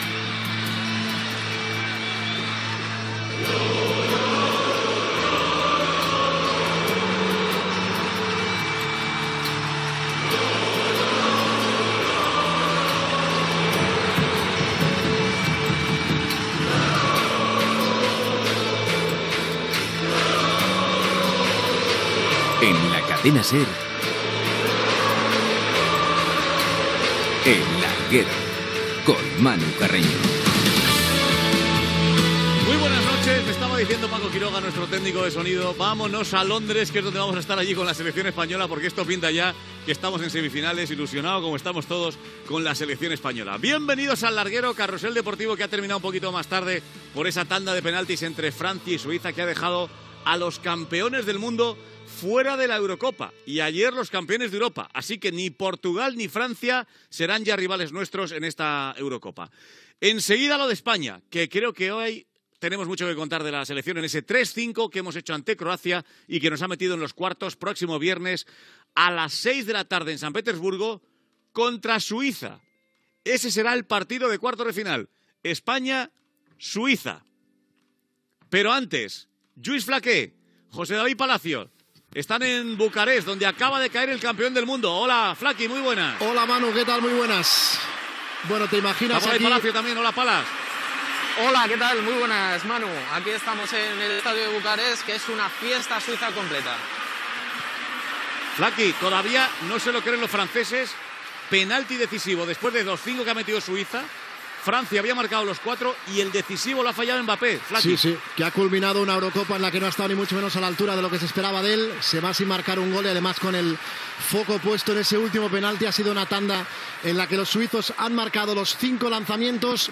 Careta del programa, presentació, informació de la Copa d'Europa de futbol masculí. Connexió amb Bucarest amb el partit França Suïssa, que ha acabat amb la victòria de la segona
Esportiu